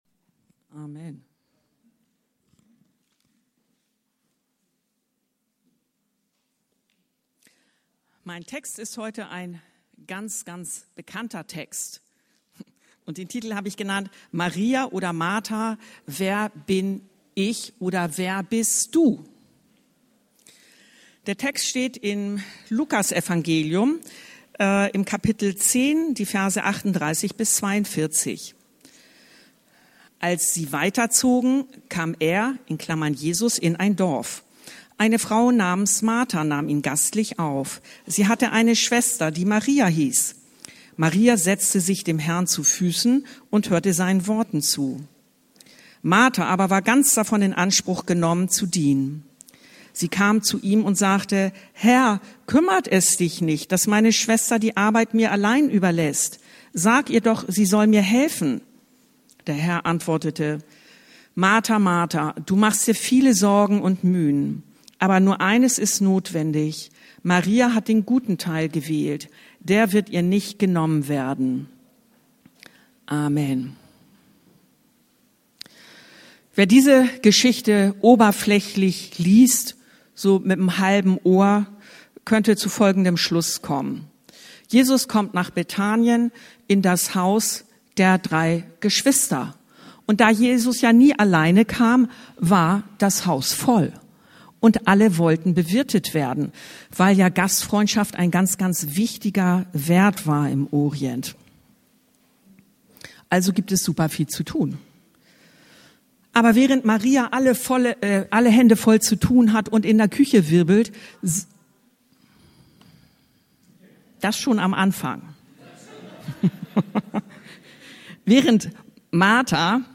Maria oder Martha oder wer bin Ich? Luk 10,38-42 ~ Anskar-Kirche Hamburg- Predigten Podcast